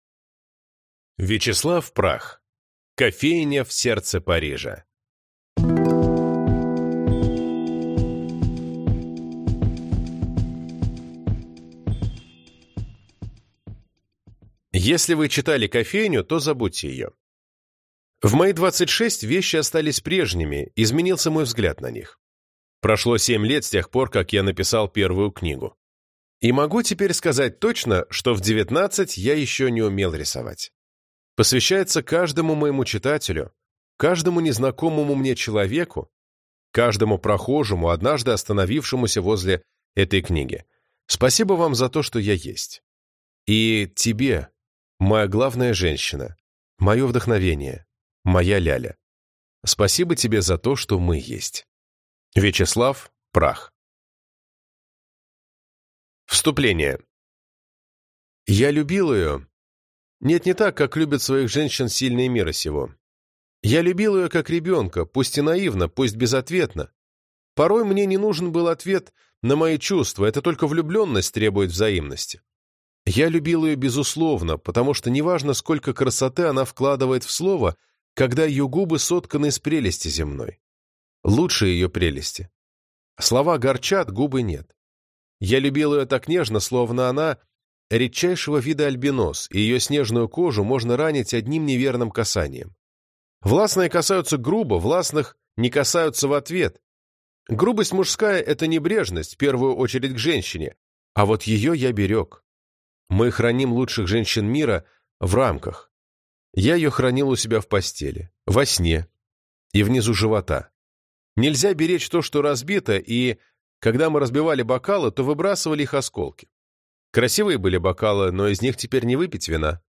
Аудиокнига Кофейня в сердце Парижа | Библиотека аудиокниг